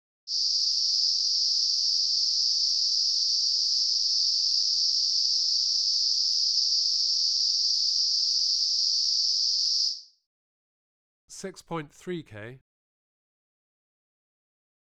34 - 5kHz.wav